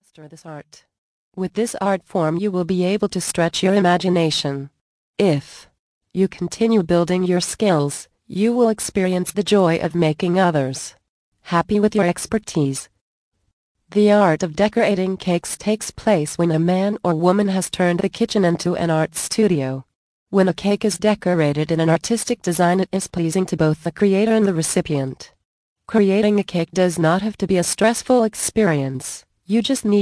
Expert Cake Decorating Made Easy audio book + FREE Gift